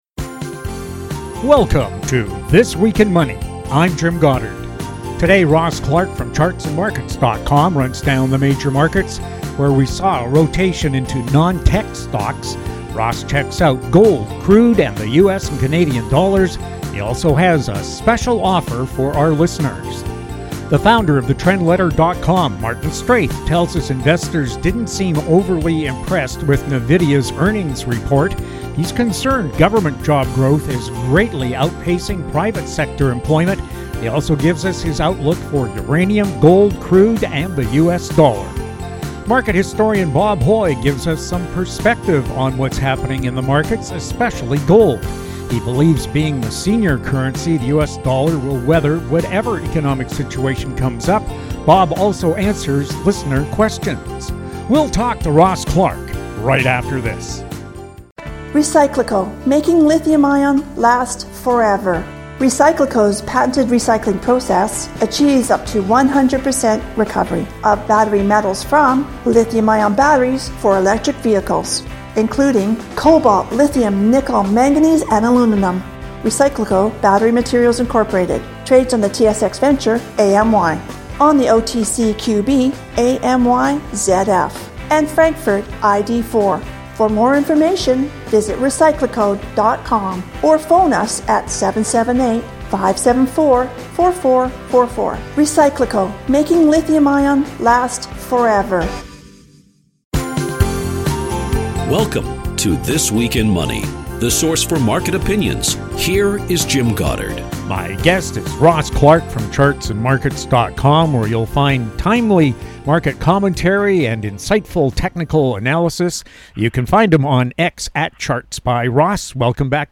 August 31, 2024 | This Week in Money This Week in Money Visit Show Archives This Week in Money presents leading financial news and market commentary from interesting, informative and profound guests. They are some of the financial world's most colorful and controversial thinkers, discussing the markets, economies and more!
New shows air Saturdays on Internet Radio.